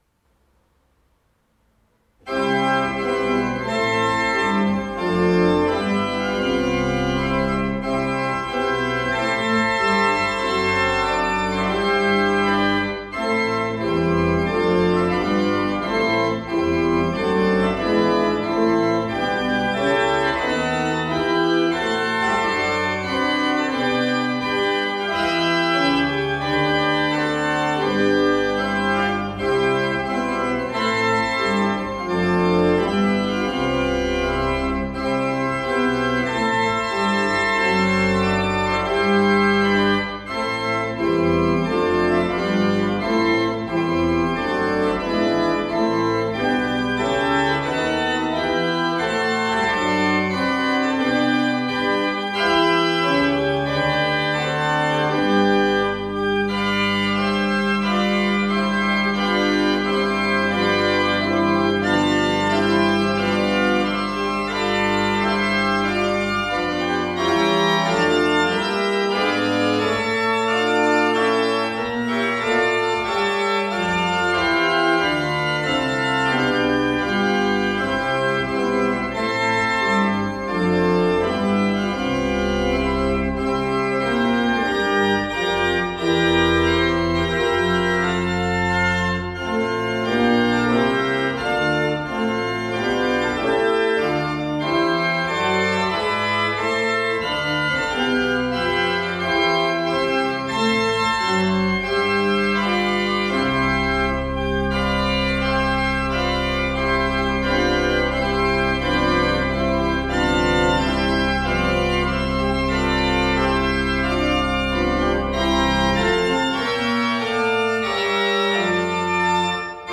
Orgel
Unsere Orgel (Orgel-Mayer, Heusweiler) wurde im Sommer 2012 durch die Orgelbaufirma Förster & Nicolaus (Lich) gründlich gereinigt und repariert und hat einen vollen und harmonischen Klang.